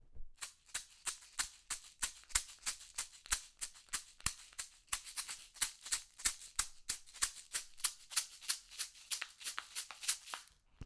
Hochet peau de cerf ref. 13
Hochet chamanique artisanal en peau de cerf et bois de cornouiller – Perles